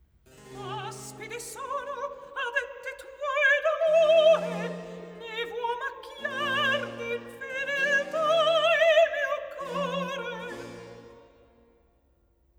Act 1_ Recit_ Aspide sono